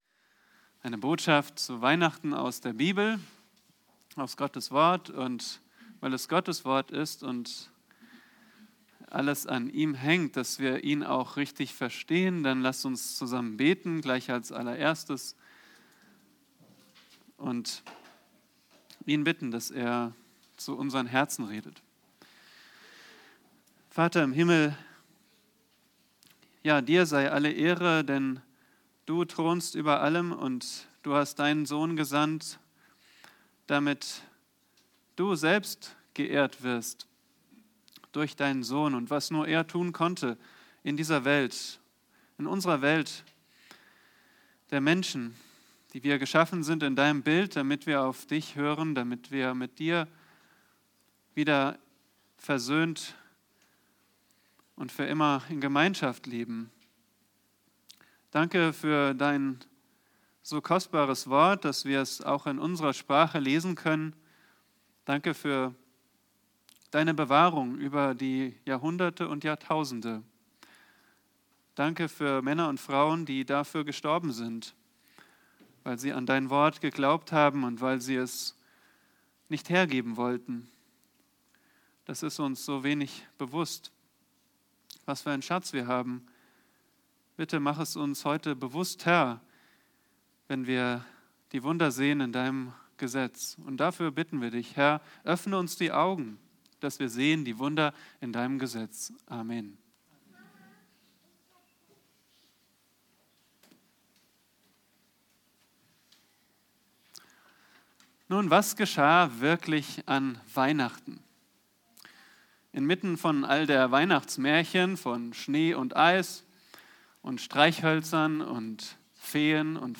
Predigten nach Serien: Sie können gern diesen Dienst durch Ihre Spende unterstützen: